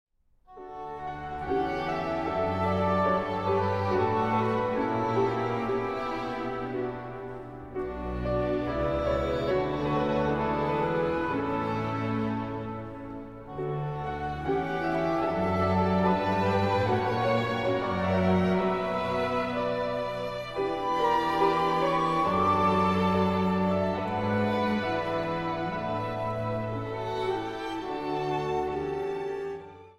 4 stemmen
Zang | Gemengd koor